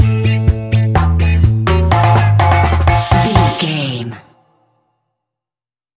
Classic reggae music with that skank bounce reggae feeling.
Uplifting
Ionian/Major
laid back
off beat
drums
skank guitar
hammond organ
percussion
horns